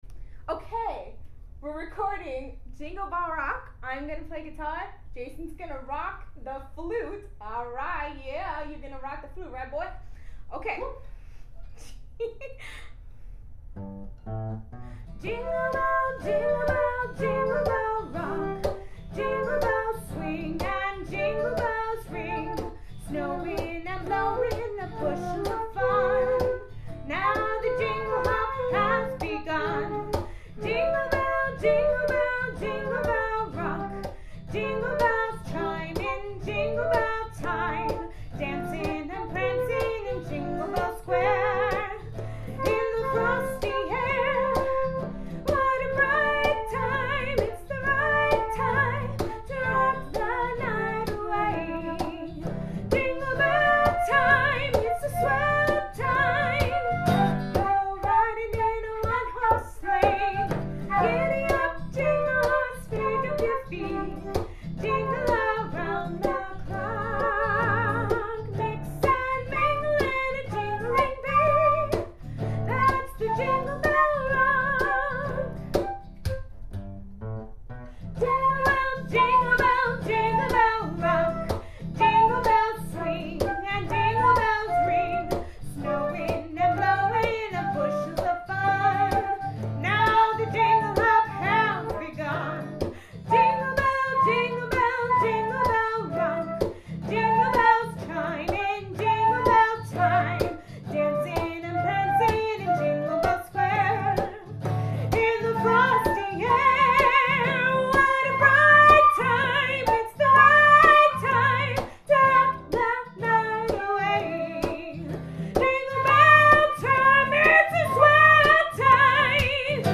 Here’s from our rehearsal tonight.
guitar
flute